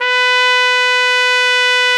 Index of /90_sSampleCDs/Roland L-CDX-03 Disk 2/BRS_Piccolo Tpt/BRS_Picc.Tp 1